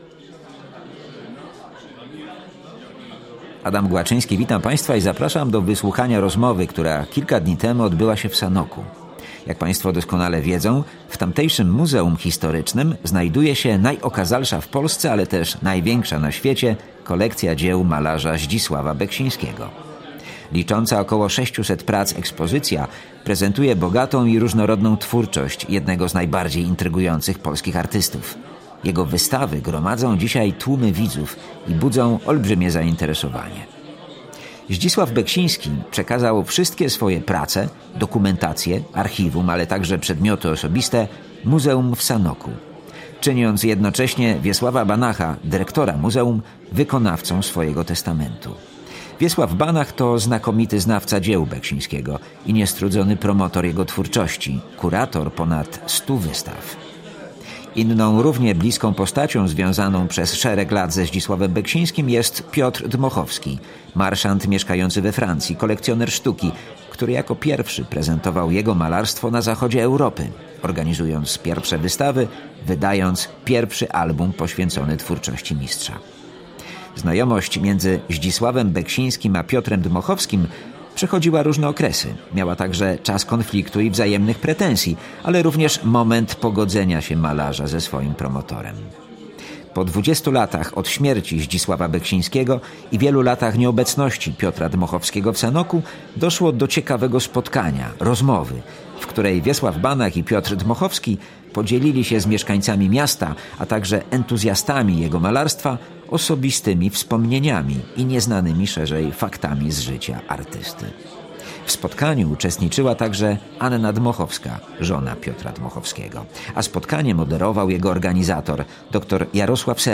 Rozmowa miała charter otwarty, przysłuchiwało się jej bardzo wielu mieszkańców Sanoka, miłośników twórczości Beksińskiego.
DebataoBeksinskim.mp3